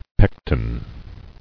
[pec·ten]